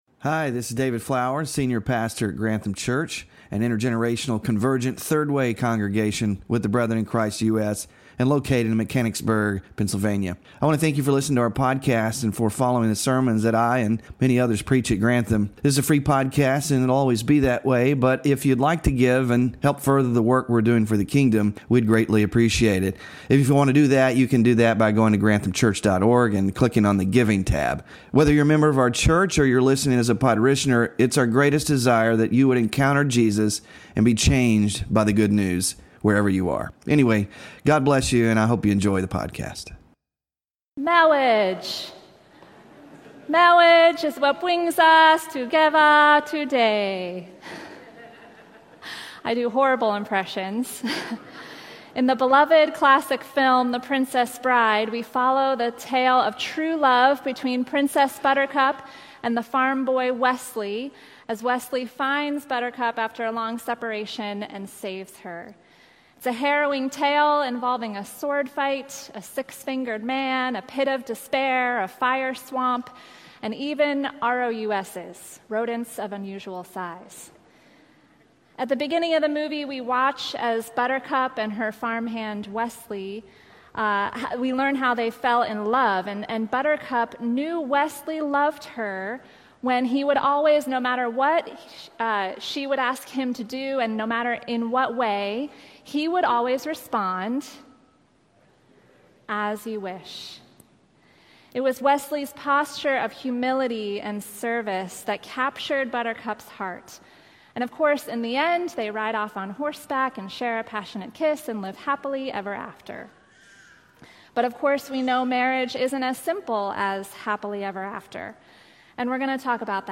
WORSHIP RESOURCES Faithfulness in the Home - Sermon Slides (5 of 9) Small Group Discussion Questions (None) Bulletin (8-3-25)